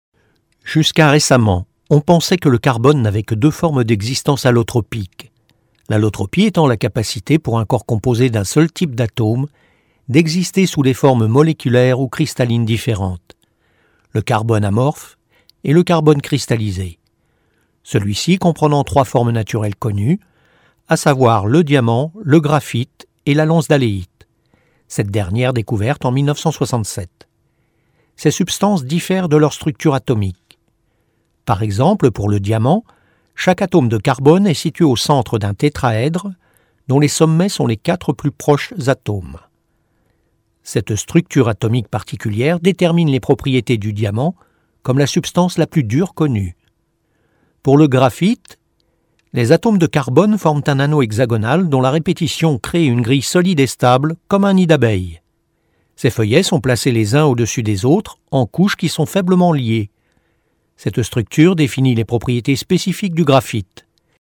Comédien depuis vingt ans, ma voix au timbre médium sait être selon les exigences,tour à tour,posée,sérieuse,claire, pédagogique,explicative, rassurante mais aussi confidentielle, mystérieuse ou encore joueuse,exubérante, émerveillée...
Sprechprobe: eLearning (Muttersprache):